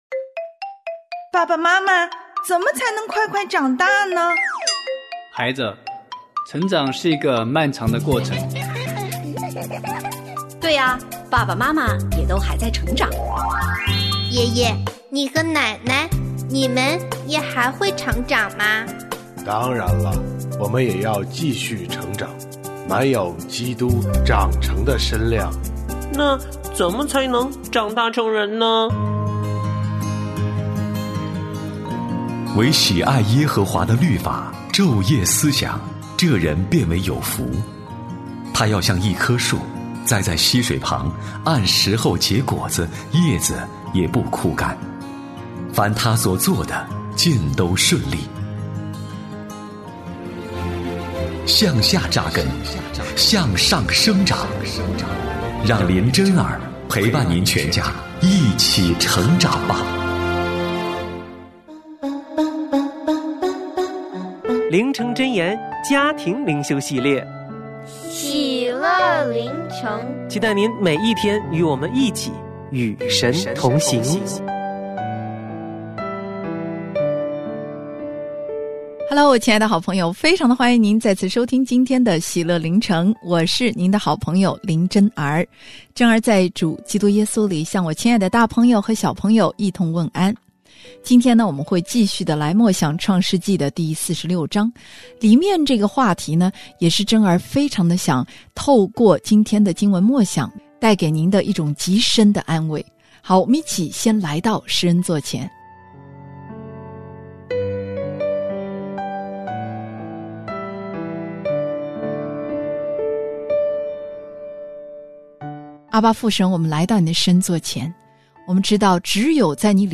我家剧场：圣经广播剧（151）耶洗别被惩罚；耶户灭绝了亚哈的子孙